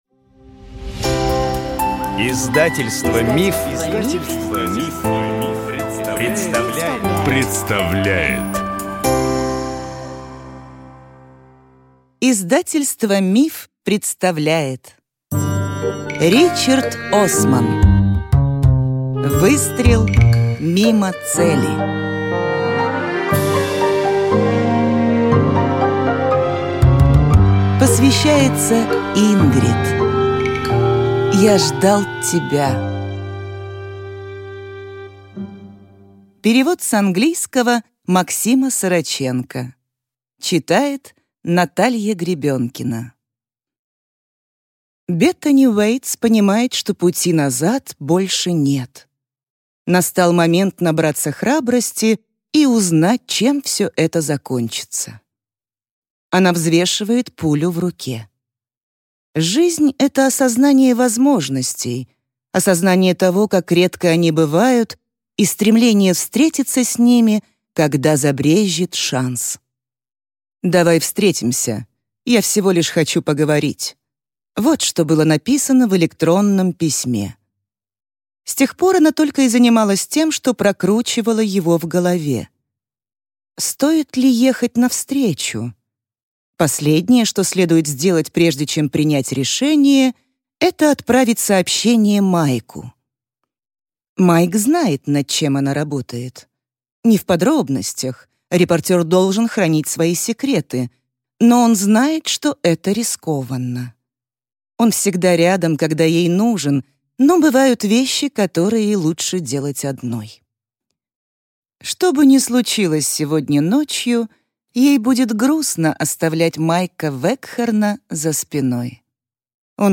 Аудиокнига Выстрел мимо цели | Библиотека аудиокниг